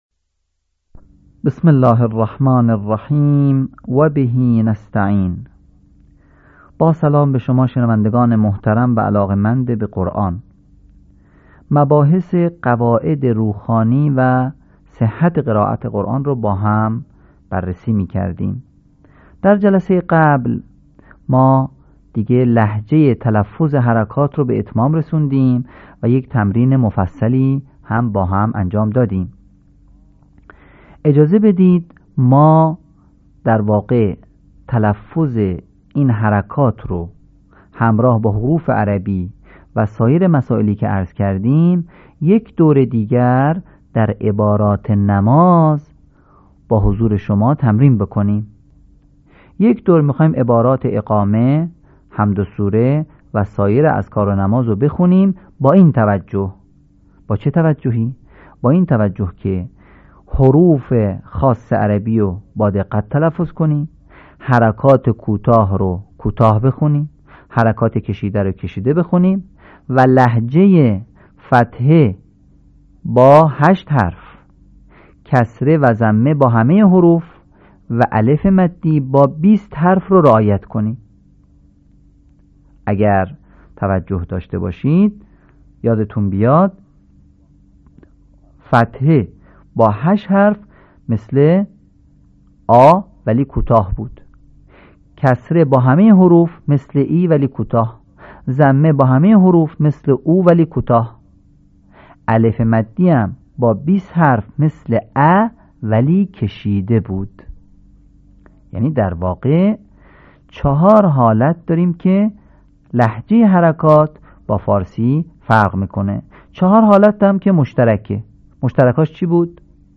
آموزش قرآن